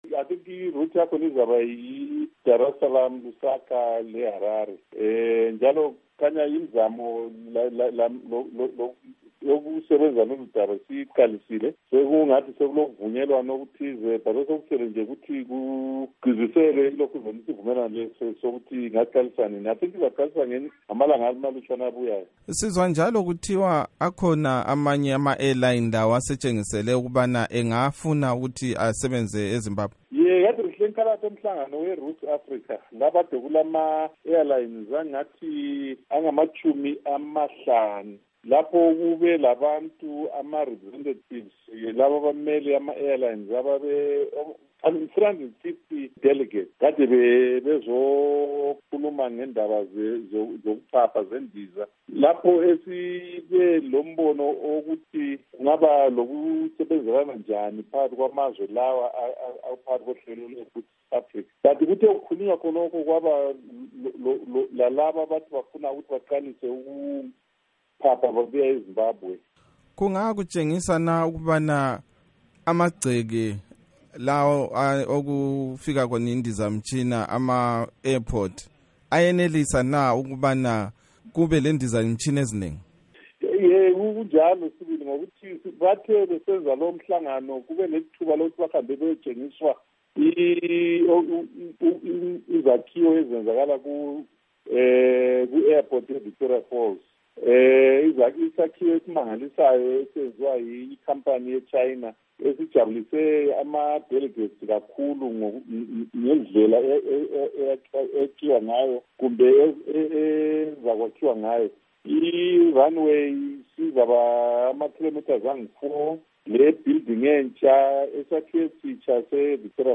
Ingxoxo loMnu. Obert Mpofu